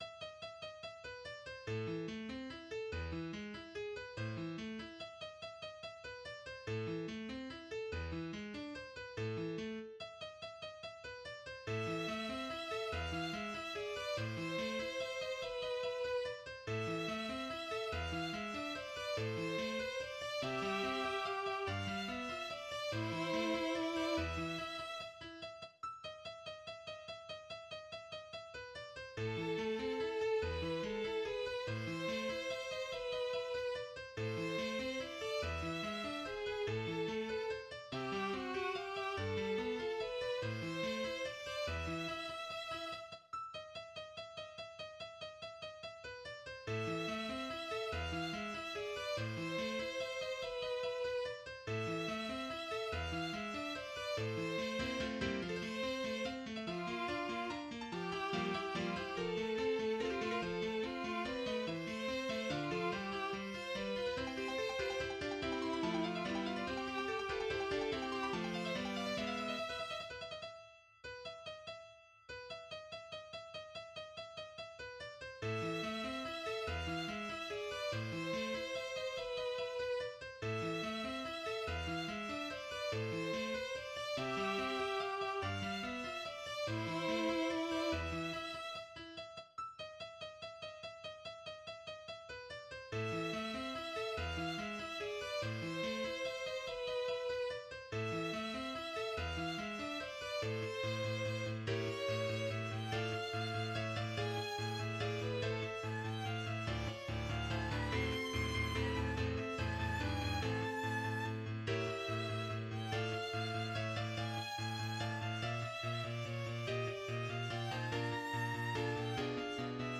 A Complete Synthesized Performance and Violin Score
Example 2 provides a complete synthesized performance of the violin-accompanied Für Elise; the score for the violin part is included in Figure 6.
• Output (Generated Violin)
Example 2. A complete synthesized performance of Für Elise with generated violin accompaniment.